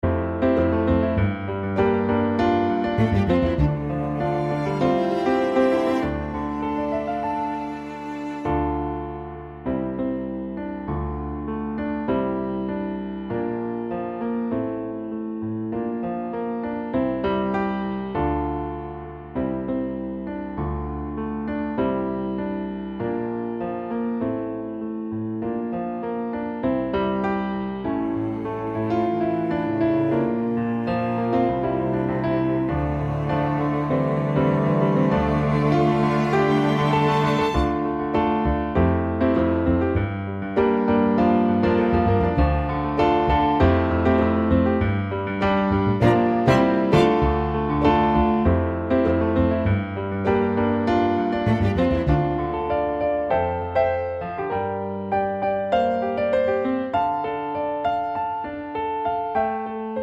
with Strings